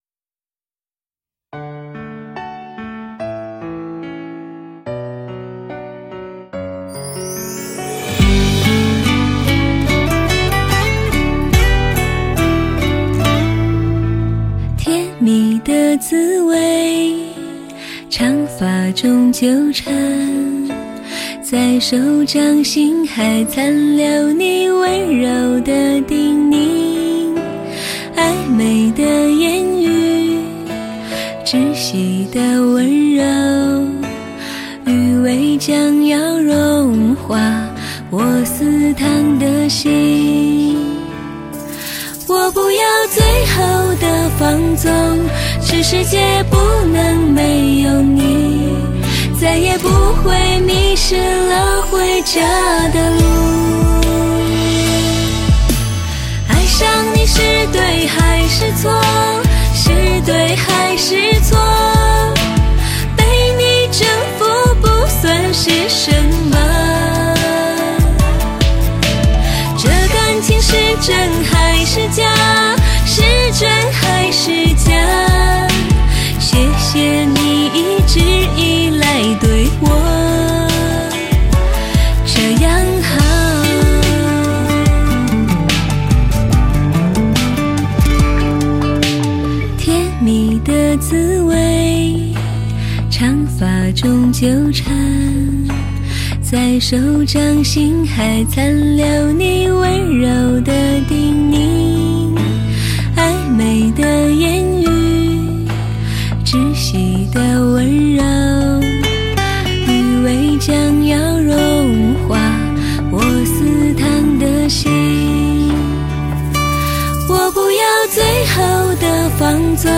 经典流行